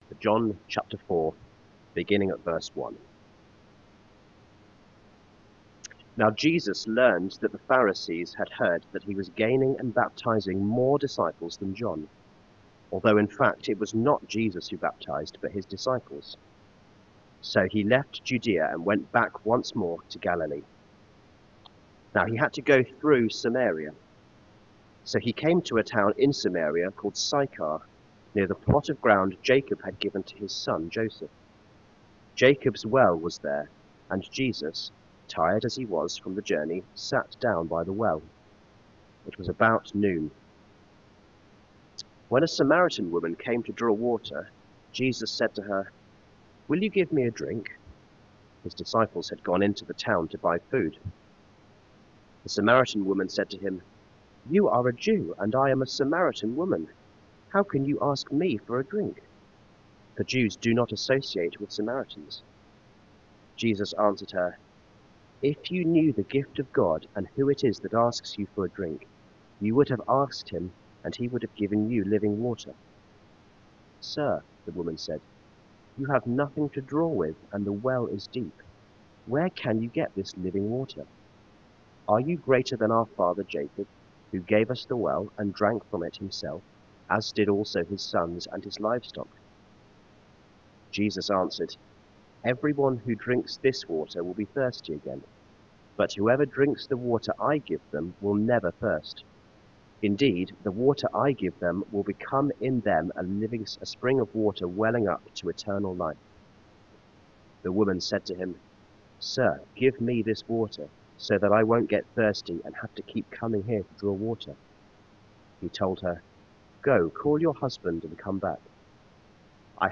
A talk about walking away from Jesus December 7